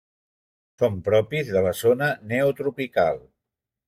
Pronounced as (IPA) [ˈpɾɔ.pis]